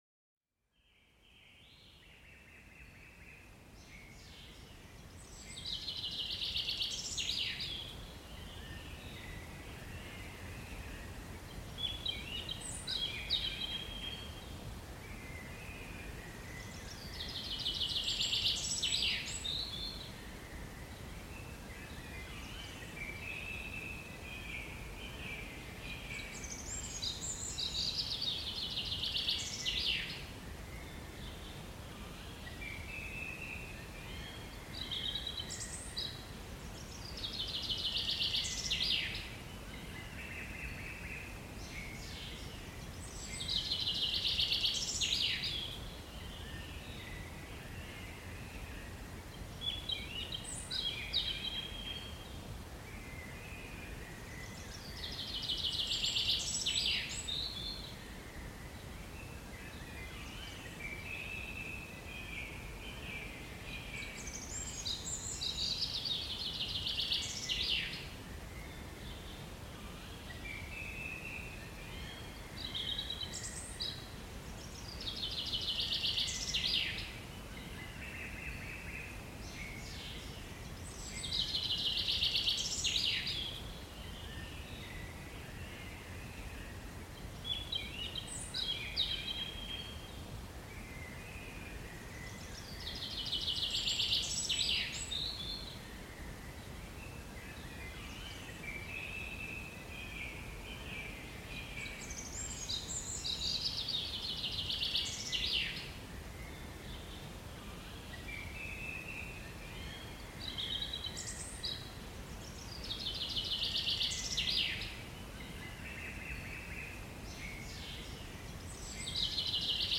Écoutez les chants des oiseaux pour une relaxation optimale
Découvrez la beauté des chants d'oiseaux, un remède naturel contre le stress. Les mélodies variées de nos amis à plumes vous plongent dans un univers apaisant et revitalisant.
Plongez dans notre univers sonore et laissez les bruits apaisants de la nature vous envelopper.